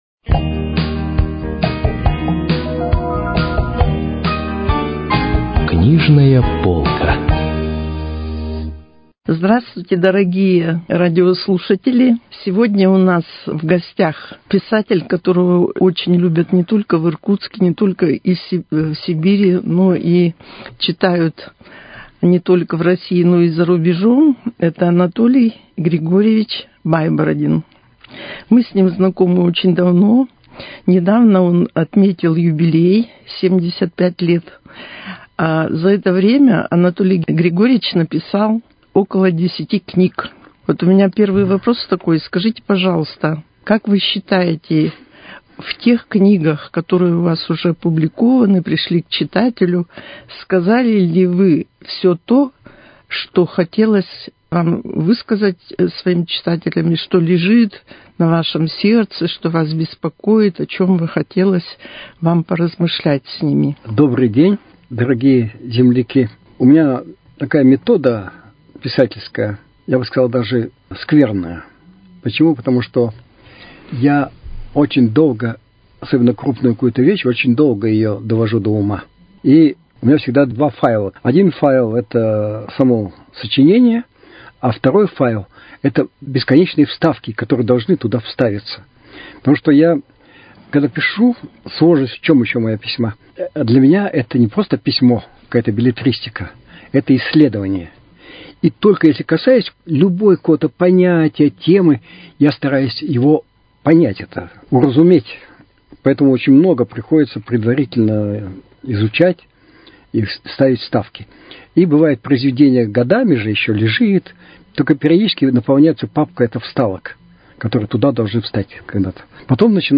Книжная полка: Беседа